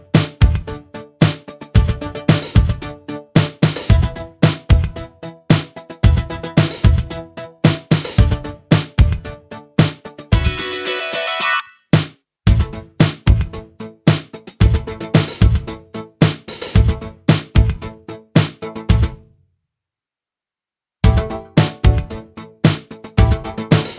Minus Guitar Pop (1980s) 3:48 Buy £1.50